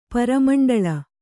♪ para maṇḍaḷa